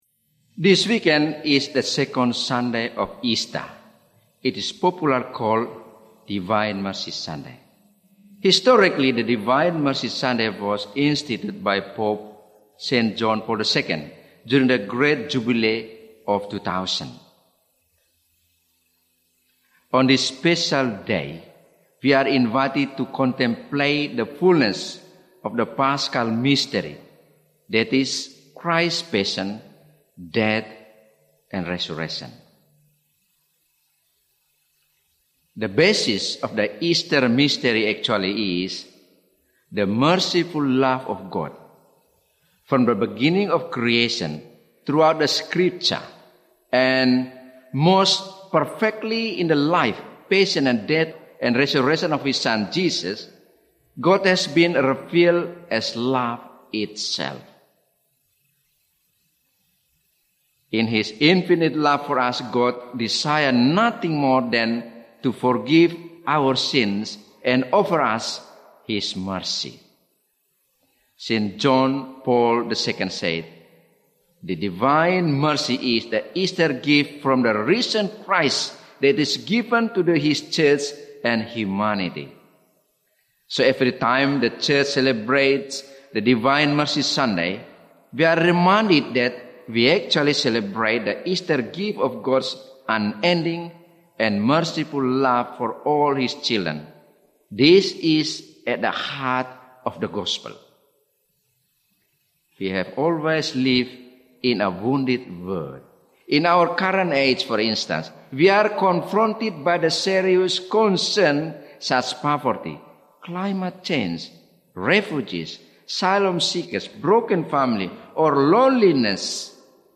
Archdiocese of Brisbane Second Sunday of Easter (Divine Mercy Sunday) - Two-Minute Homily